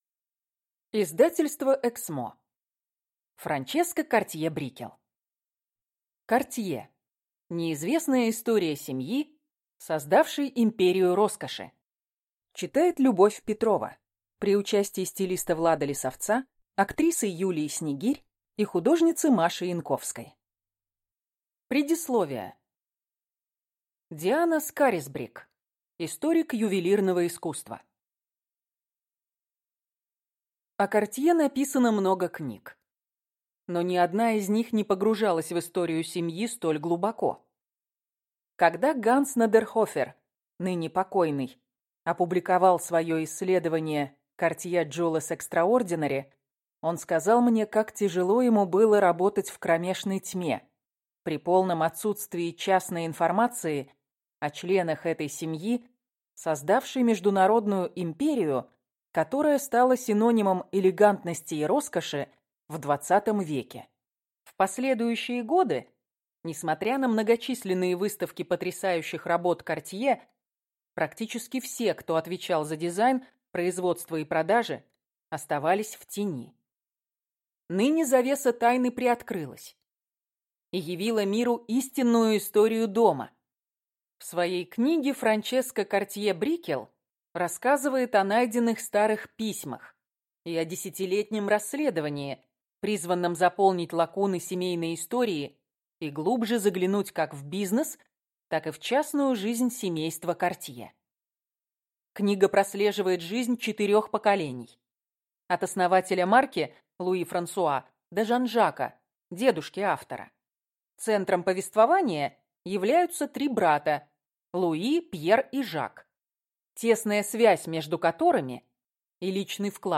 Аудиокнига Картье. Неизвестная история семьи, создавшей империю роскоши | Библиотека аудиокниг